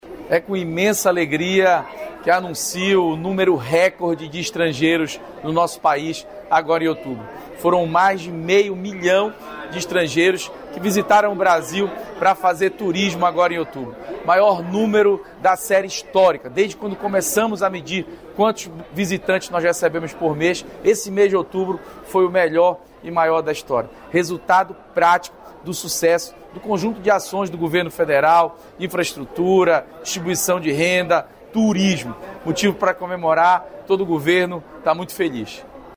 Em conversa com jornalistas na manhã desta quarta-feira (6), o ministro da Fazenda Fernando Haddad afirmou que é preciso ter cautela interna em virtude do que está acontecendo no mundo.